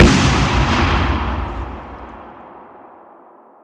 Grenade Explosion
Awesome grenade explosion sound effect. Great big boom sound.